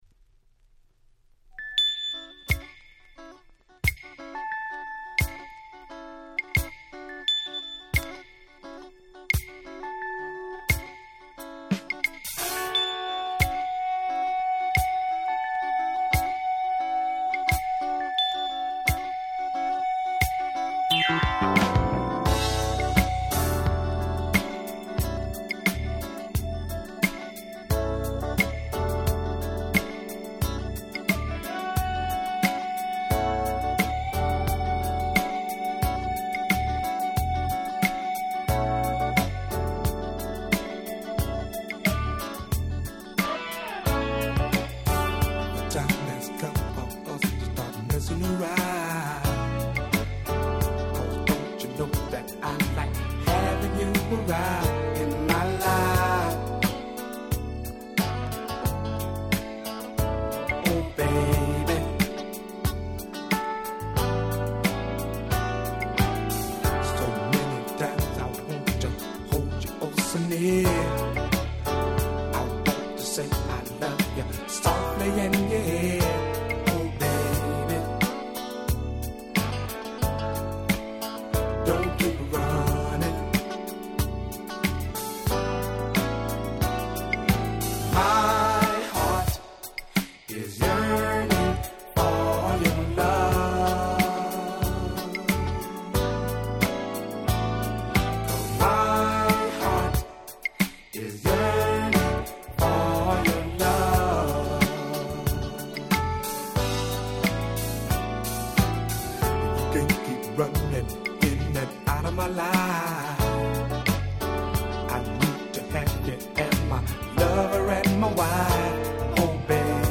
80' Nice Funk/Soul !!